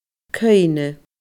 cuimhne /kɯ̃ĩNʲə/